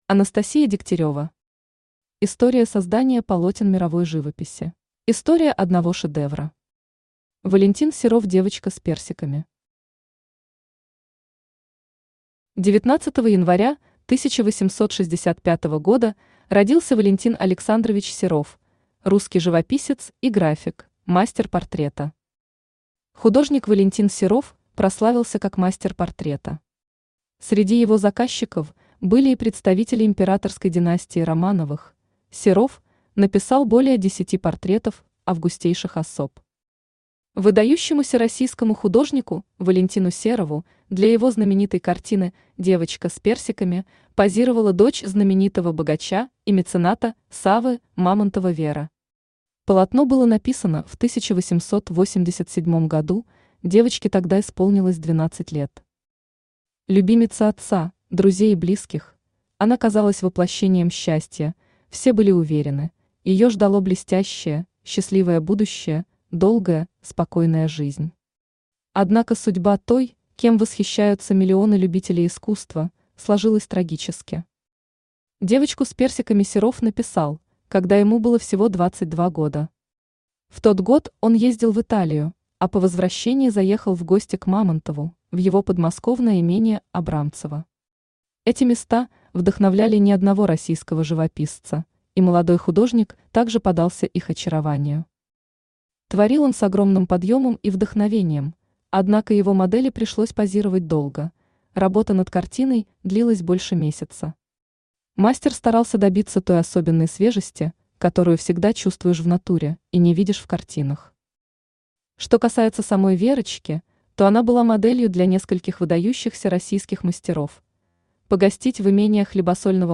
Аудиокнига История создания полотен мировой живописи | Библиотека аудиокниг
Aудиокнига История создания полотен мировой живописи Автор Анастасия Александровна Дегтярева Читает аудиокнигу Авточтец ЛитРес.